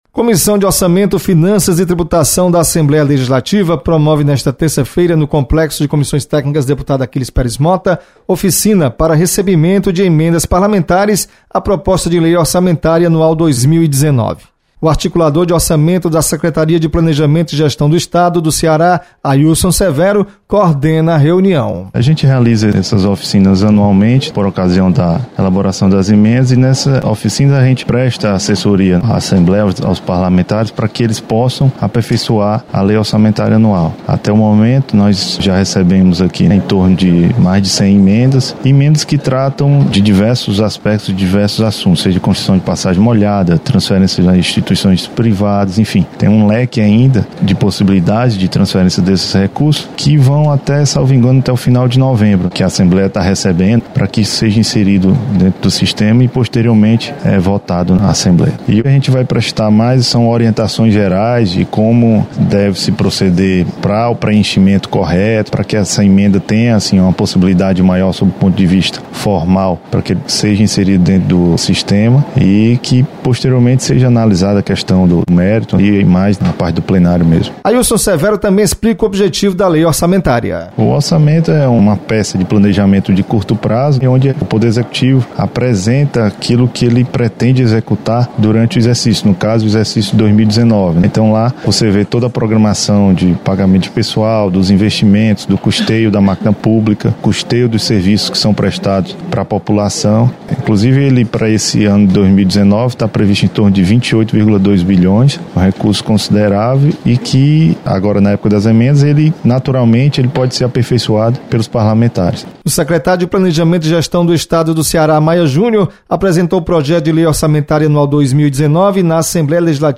Secretaria do Planejamento e Gestão orienta servidores da Comissão de Orçamento, Finanças e Tributação sobre emendas ao Orçamento. Repórter